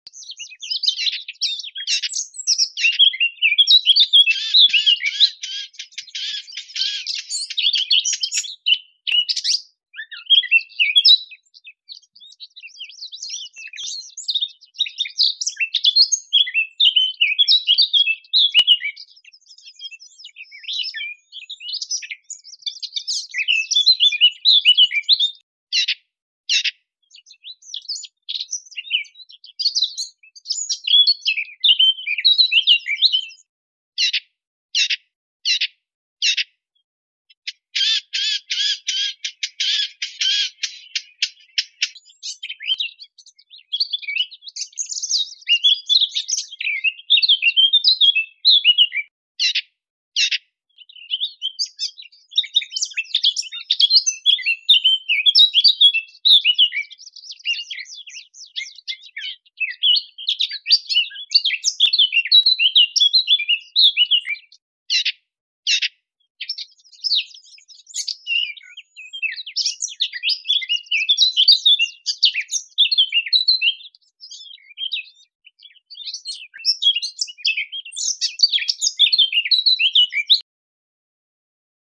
Blackcap Call & Song 🤍 Sound Effects Free Download
Blackcap Call & song 🤍 sound effects free download